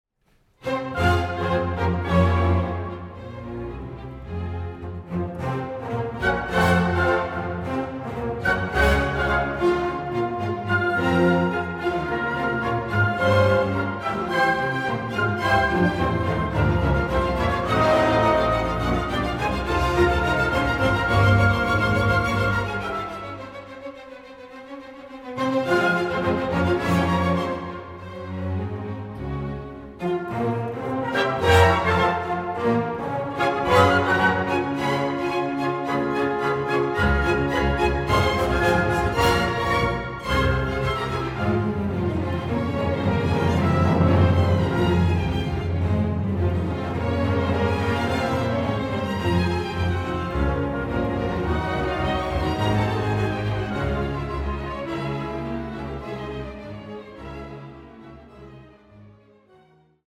ein großes Orchester
Der Live-Mitschnitt zeigt